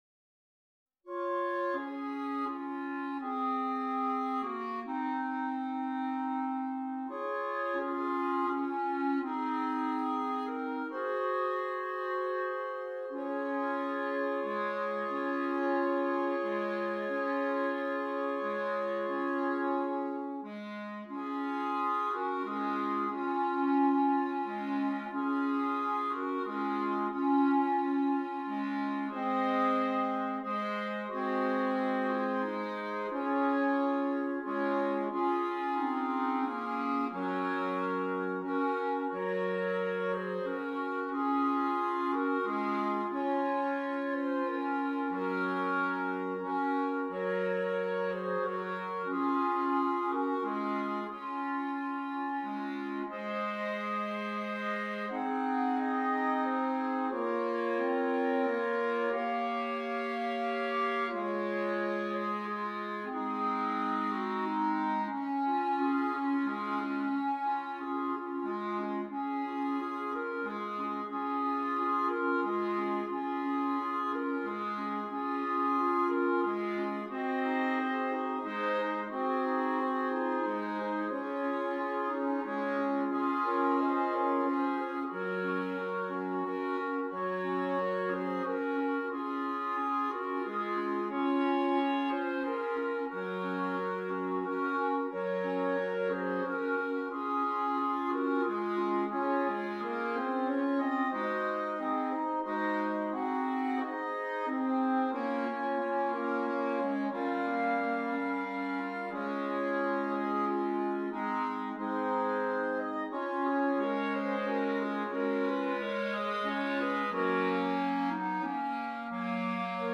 Christmas
6 Clarinets